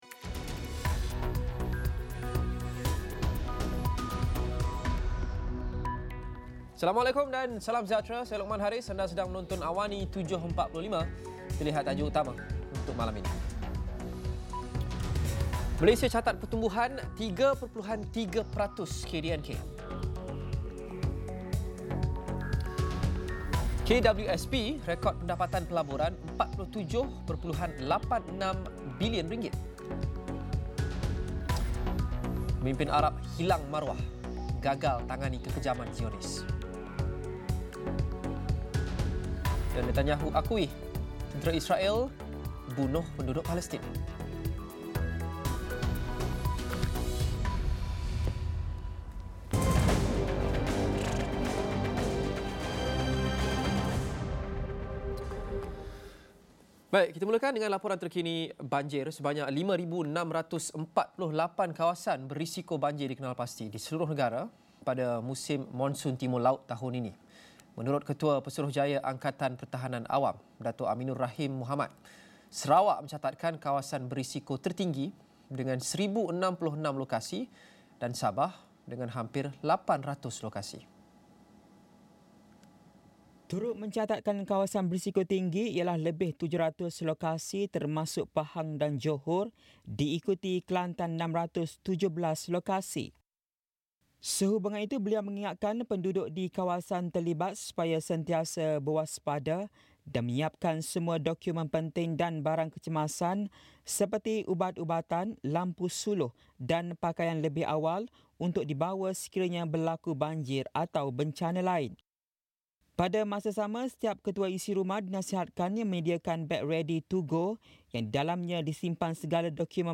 Laporan berita padat dan ringkas